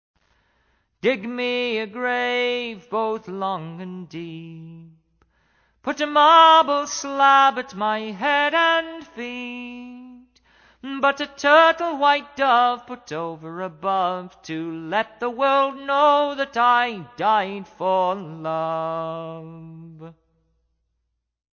English Folksongs
Recorded and mixed at Soundesign, Brattleboro, VT